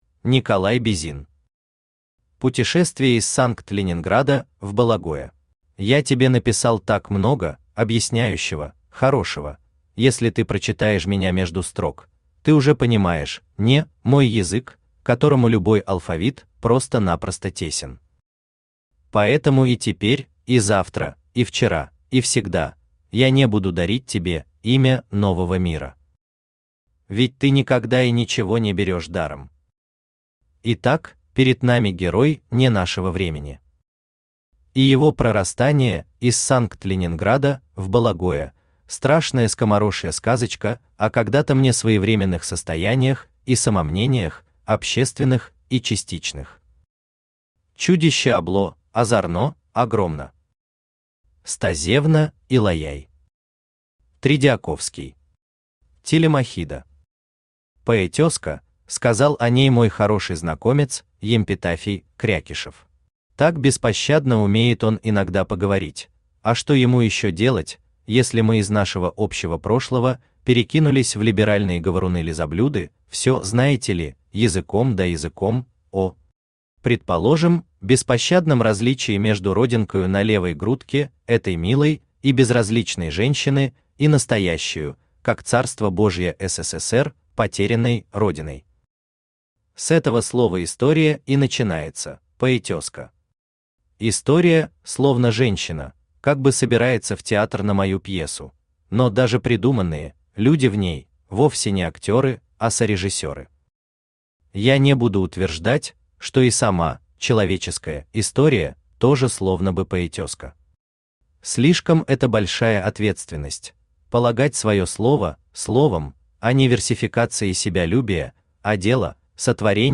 Аудиокнига Путешествие из Санкт-Ленинграда в Бологое | Библиотека аудиокниг
Aудиокнига Путешествие из Санкт-Ленинграда в Бологое Автор Николай Бизин Читает аудиокнигу Авточтец ЛитРес.